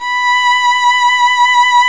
Index of /90_sSampleCDs/Roland L-CD702/VOL-1/STR_Violin 1-3vb/STR_Vln2 % + dyn
STR  VL B 6.wav